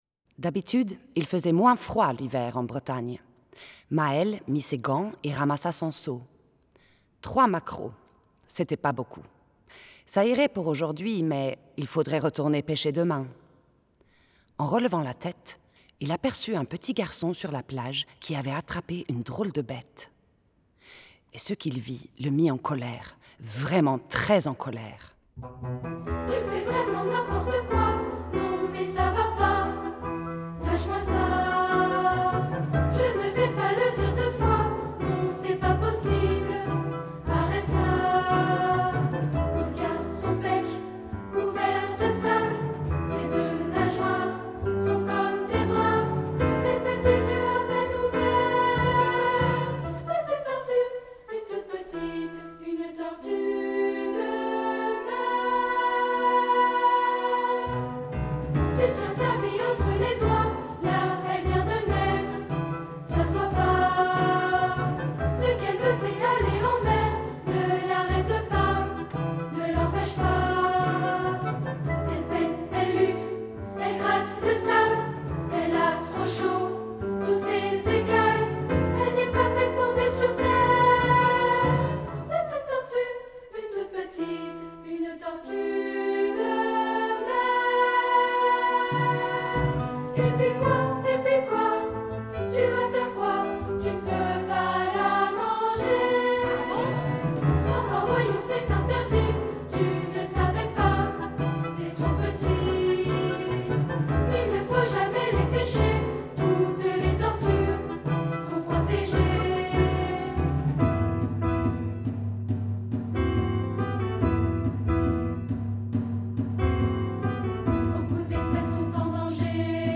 Un conte musical pour enfants sur la mer, les océans et les menaces climatiques